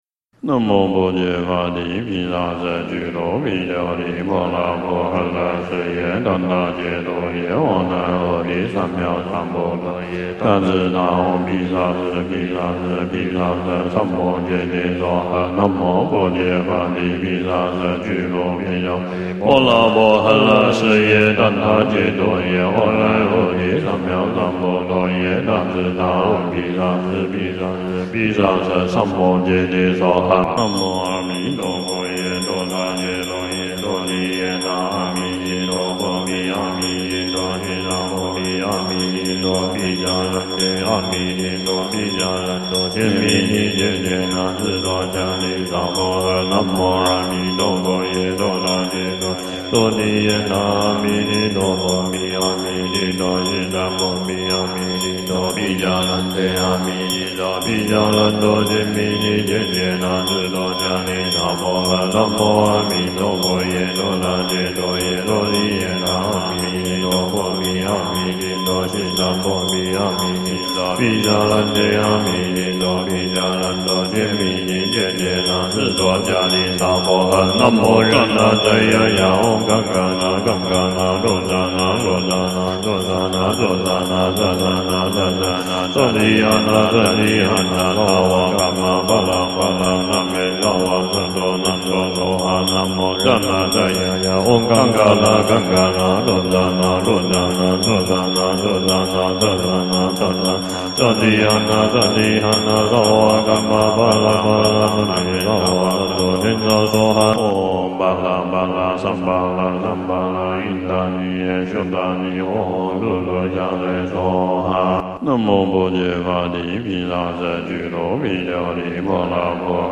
藥師心咒_往生咒_不動佛心咒_大隨求咒.mp3